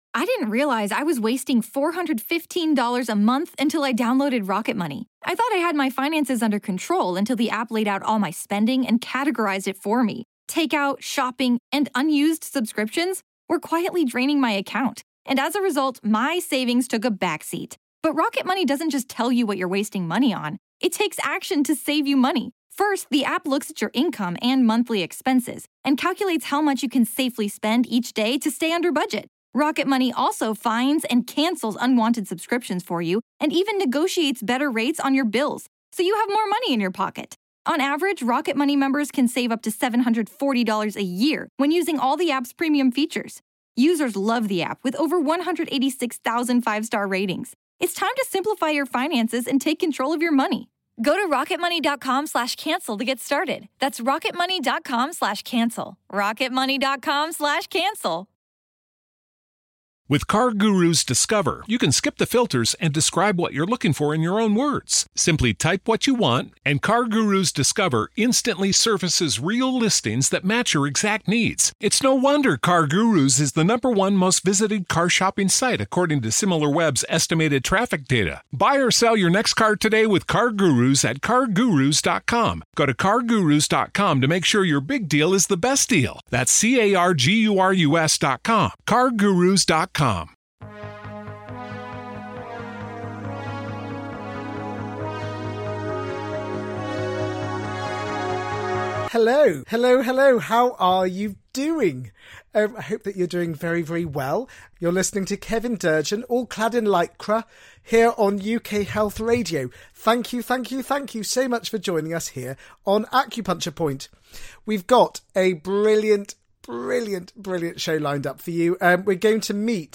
He will also play some gorgeous music to uplift your soul and get your feet tapping with happiness.